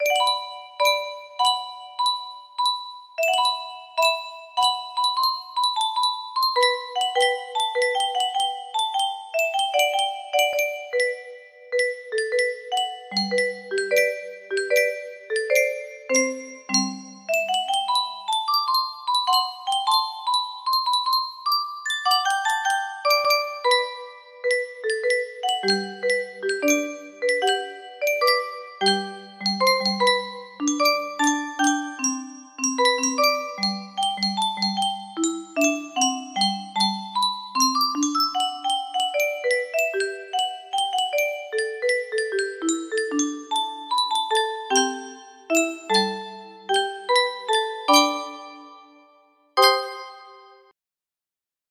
Grand Illusions 30 (F scale)
in 30-note F scale music box notation